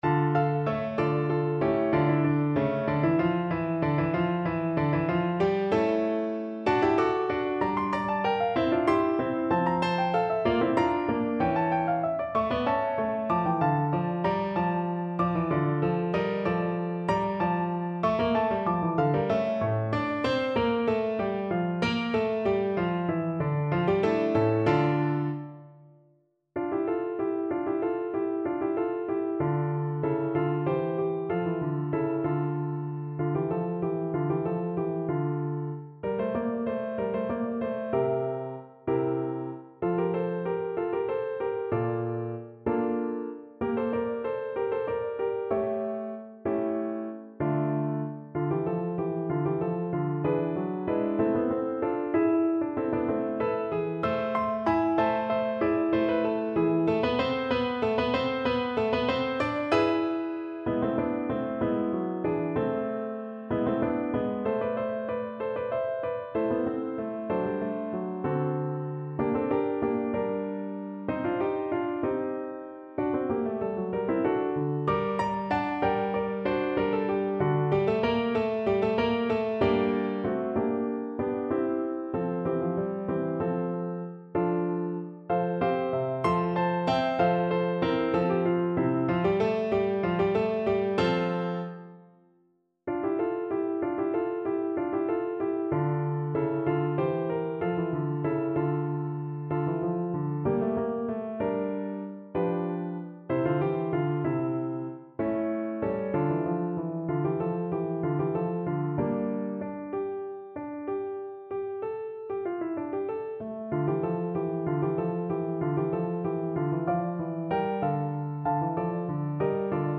= 190 Allegro (View more music marked Allegro)
Classical (View more Classical French Horn Music)